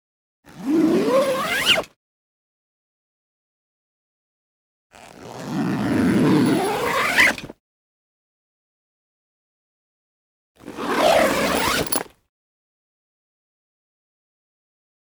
Garment Bag Zipper Luggage Sound
household